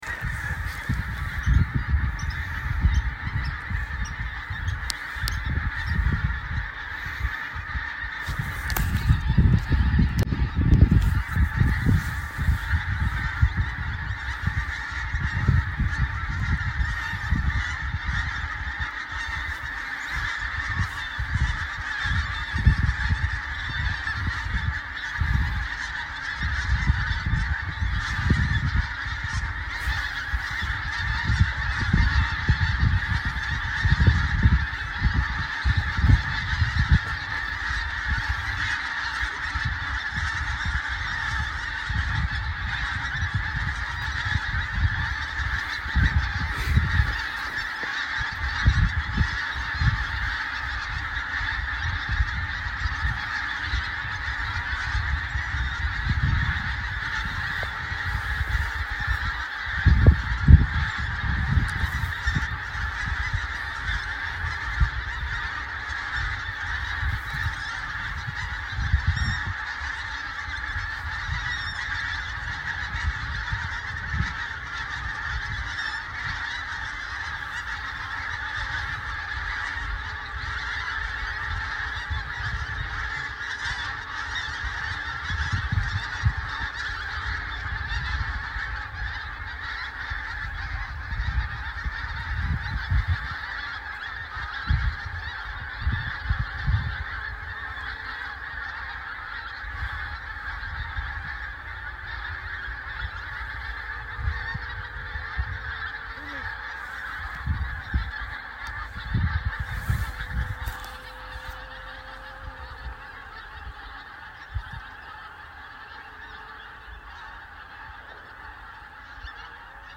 I mentioned earlier this afternoon that I was going out at dusk to see if there was any roosting activity going on amongst the birds at Blakeney, in north Norfolk.
Approximately 5,000 (and maybe more) pink-footed geese flew over us. The flock might have been at least a kilometre wide, and as this recording shows, it took more than two minutes to fly overhead. It was quite an extraordinary sight and sound.